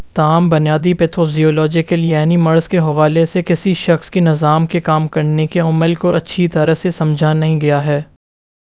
Spoofed_TTS/Speaker_08/113.wav · CSALT/deepfake_detection_dataset_urdu at main